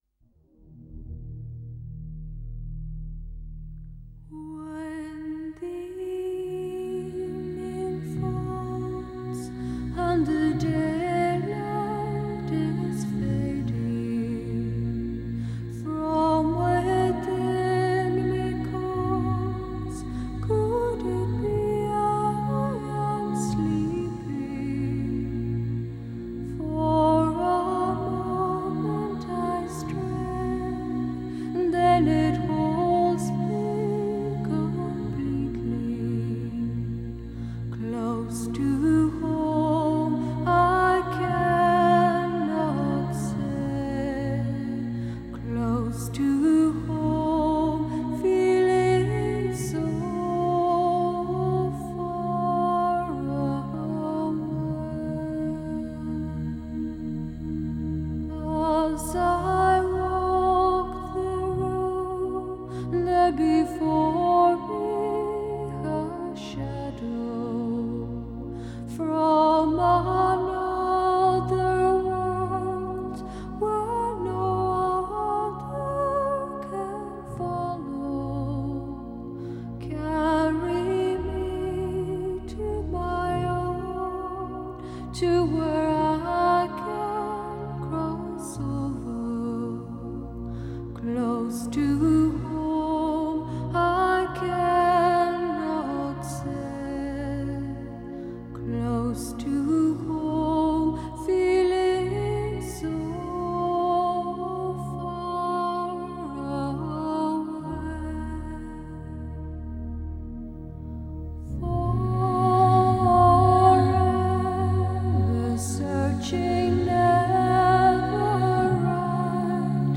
Genre: New Age
Recorded at Orinoco Studios